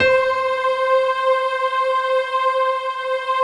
SI1 PIANO0AL.wav